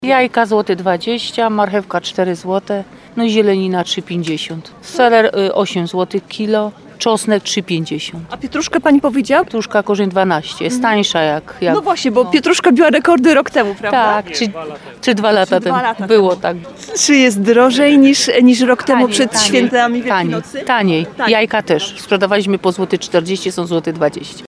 Jajka 1 zł 20 gr, marchewka 4 zł kg, seler 8 zł kg, czosnek 3 zł 50 gr, zielenina 3 zł 50 gr – wylicza jedna z pań stale handlujących na targowisku.